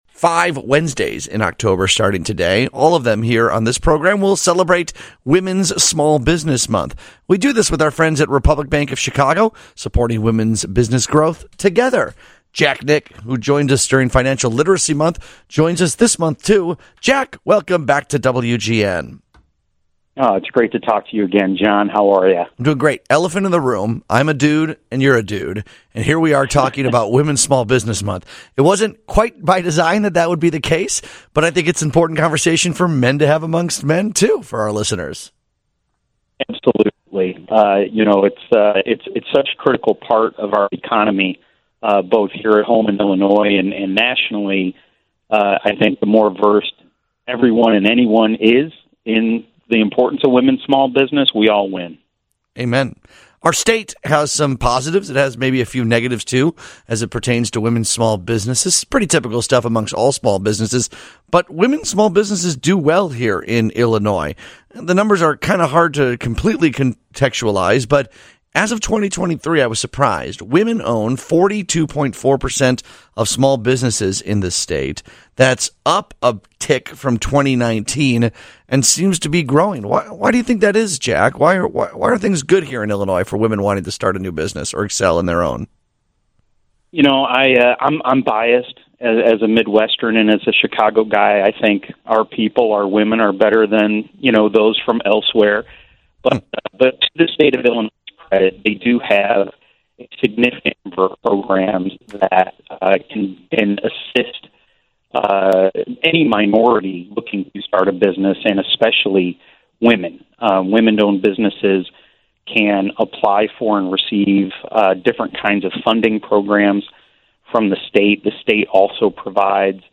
The two discuss how Chicago is supporting women entrepreneurs, including tax credits, programs, and other initiatives.